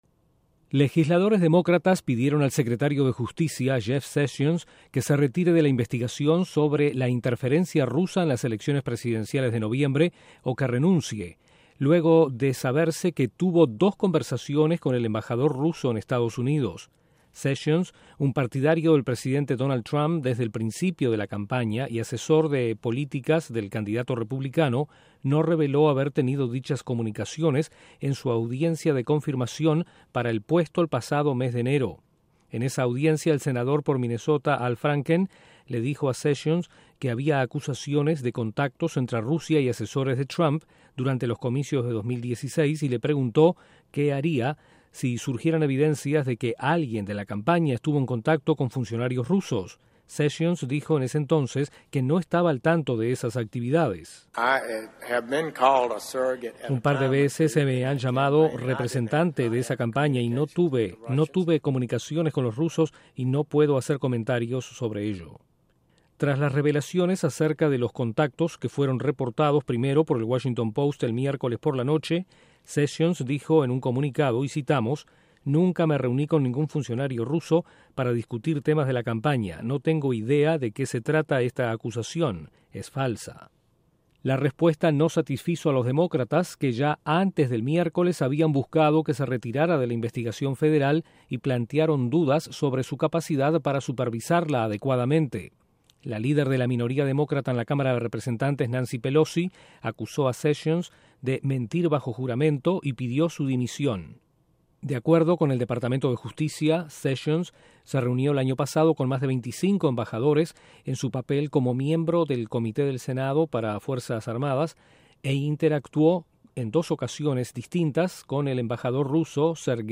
Revelan que el secretario de Justicia de EE.UU. Jeff Sessions, conversó dos veces con el embajador ruso en Washington. Desde la Voz de América en Washington informa